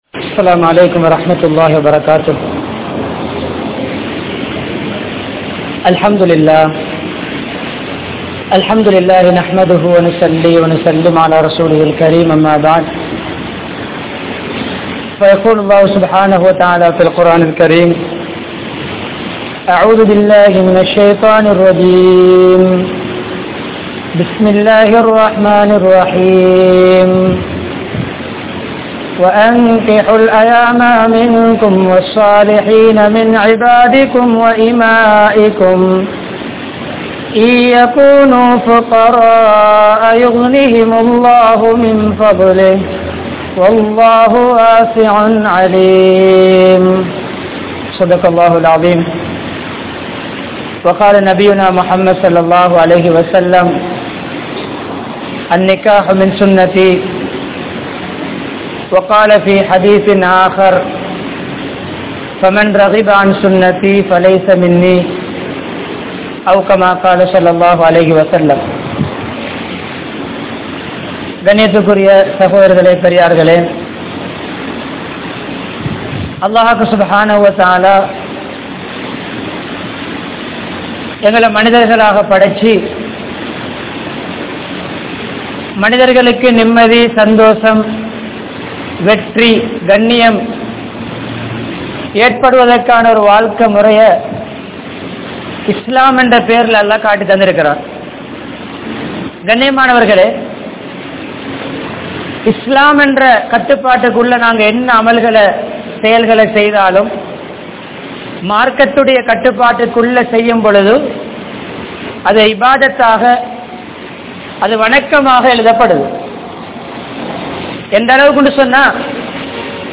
Illara Vaalkaiyai Seeralikkaatheerhal (இல்லற வாழ்க்கையை சீரழிக்காதீர்கள்) | Audio Bayans | All Ceylon Muslim Youth Community | Addalaichenai
Watthala, Peliyagoda Jumua Masjidh